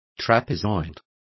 Complete with pronunciation of the translation of trapezoids.